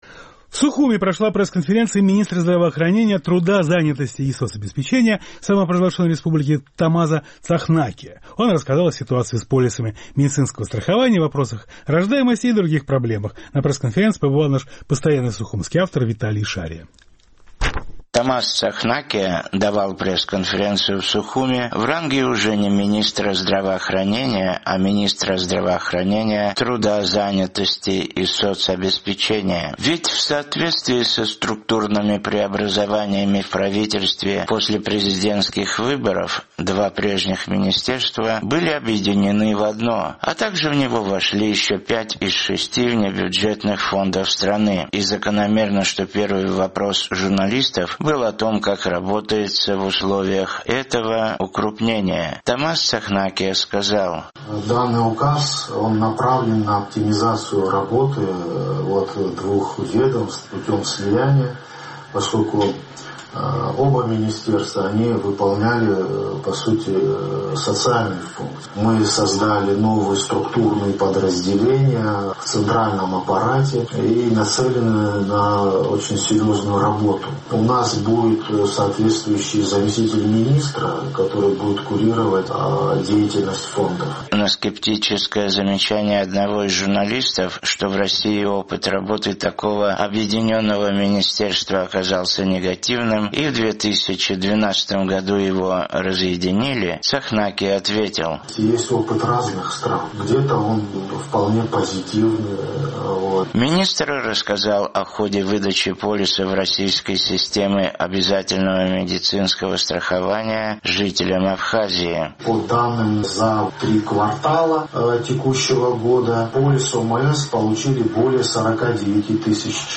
В Сухуме прошла пресс-конференция министра здравоохранения, труда, занятости и соцобеспечения Абхазии Тамаза Цахнакия. Он рассказал о ситуации с полисами медицинского страхования, вопросах рождаемости и других проблемах.